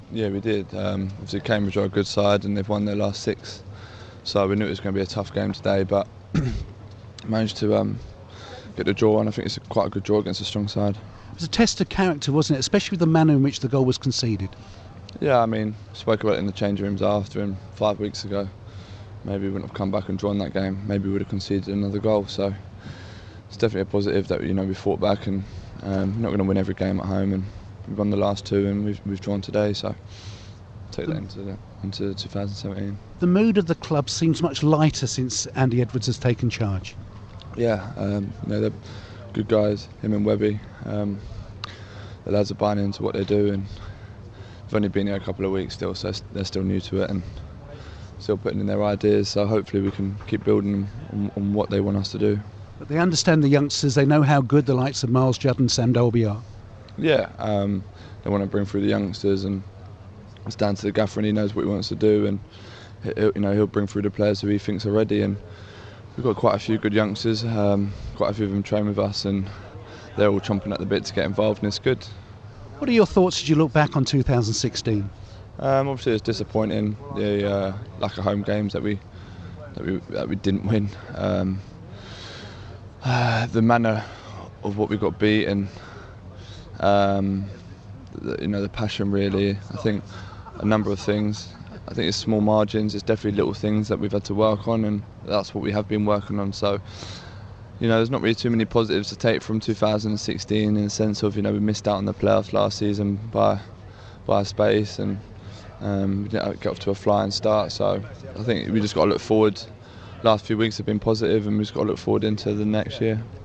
The Leyton Orient striker was speaking following the 1-1 draw with Cambridge